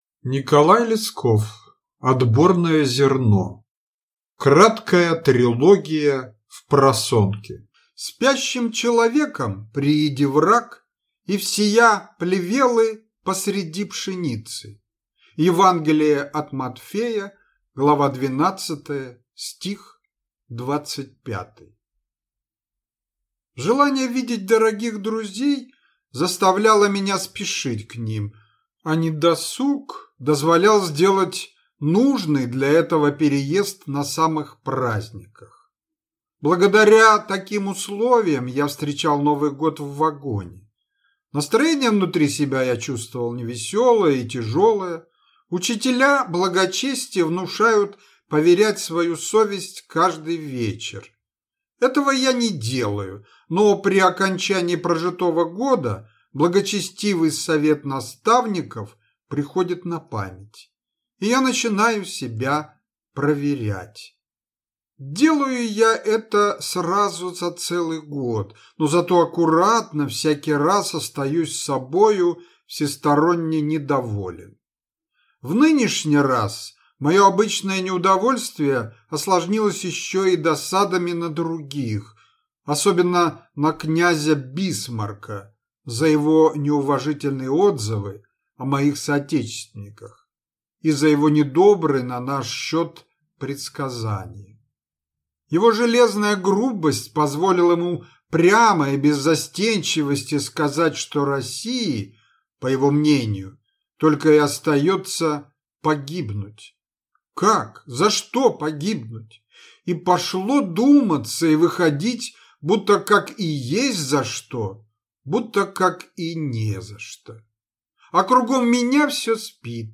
Аудиокнига Отборное зерно | Библиотека аудиокниг